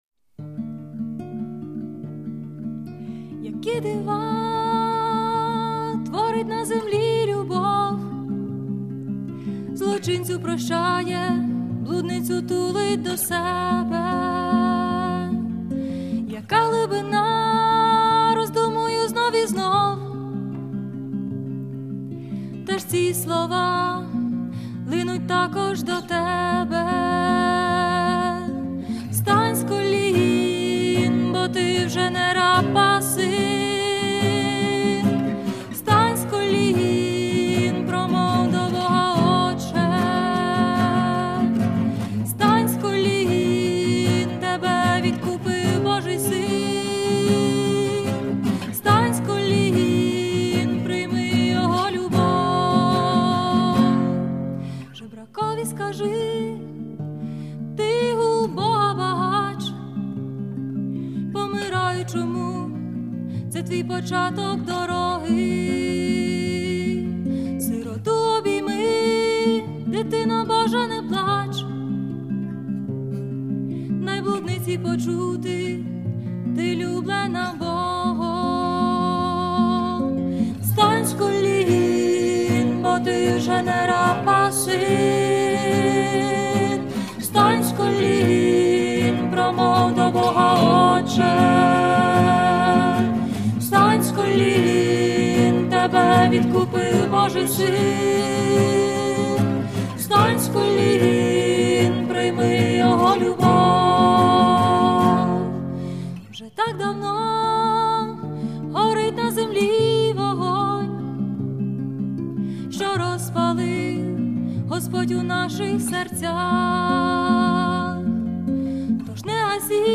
вокал
гітара
фон-вокал